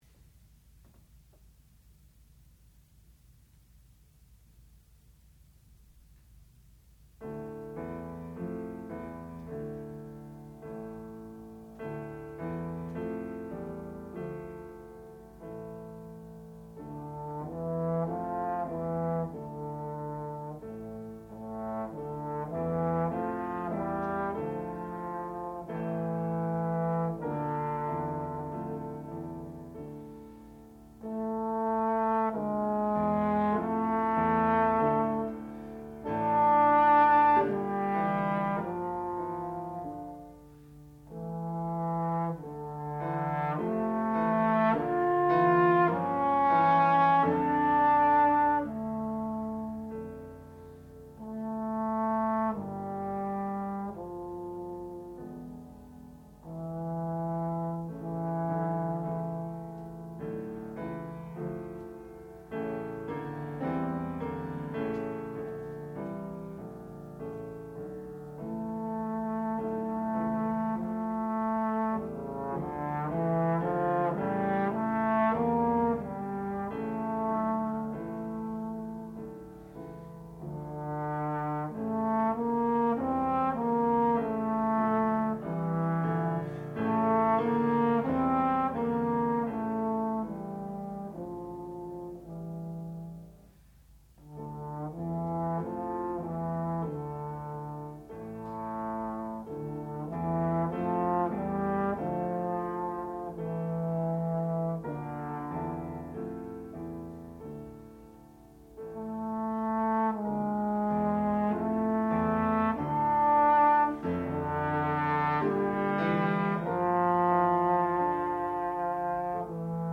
sound recording-musical
classical music
trombone
piano
Master's Recital